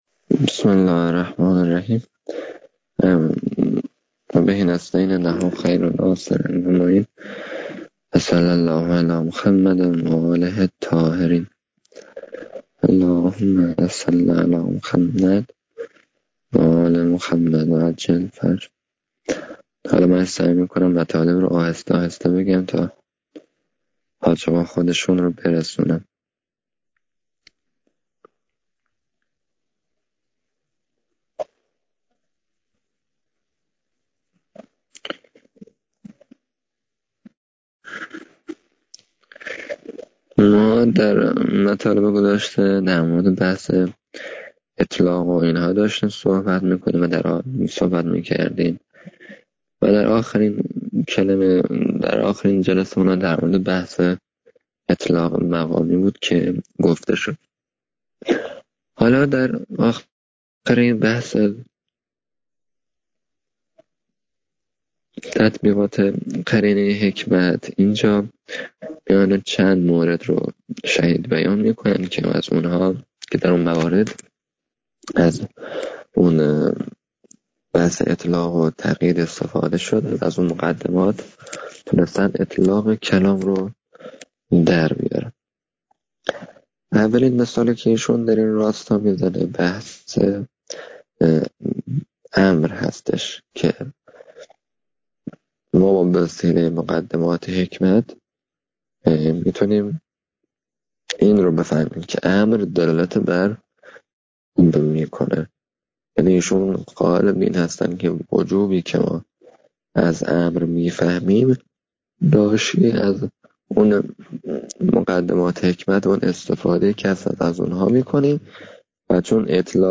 تدریس